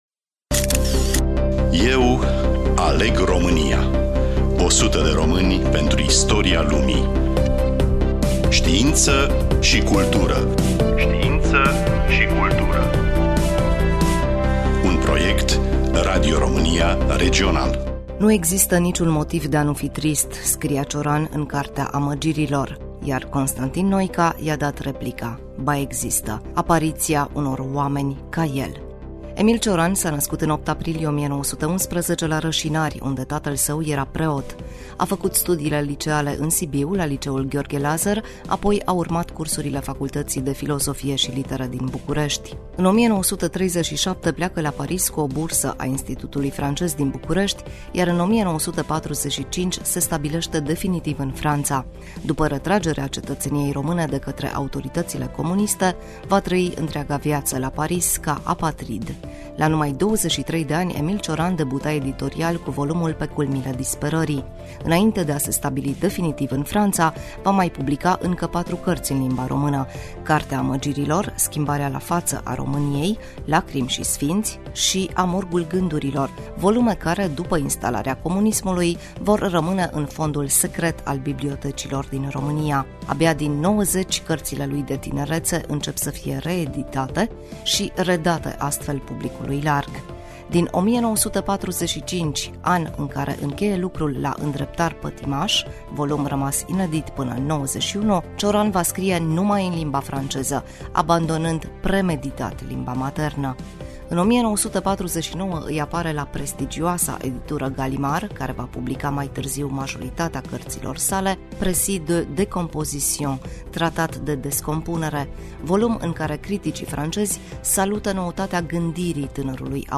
Voice-over
Prezentator